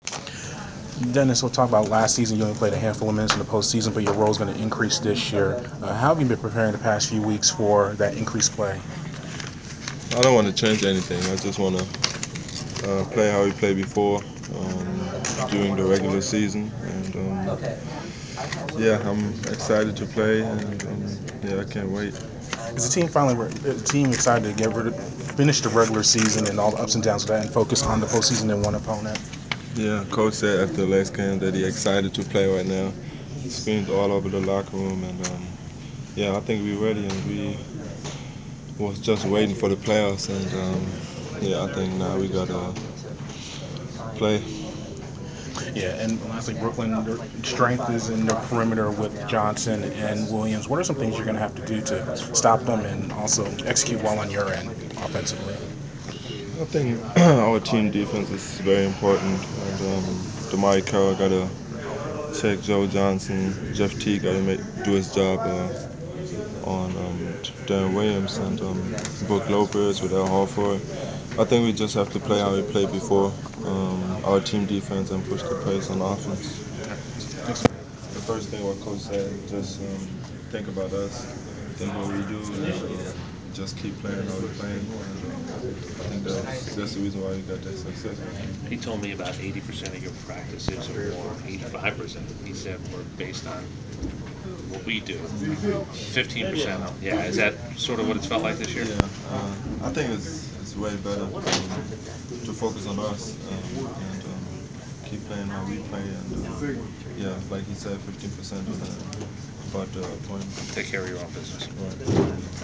Inside the Inquirer: Pregame interview with Atlanta Hawks’ Dennis Schroder (4/19/15)
We caught up with Atlanta Hawks’ guard Dennis Schroder before his team’s home playoff contest against the Brooklyn Nets in the first round of the Eastern Conference playoffs. Topics included the Hawks’ anticipation of entering the playoffs after a long regular season and the matchup against the Nets.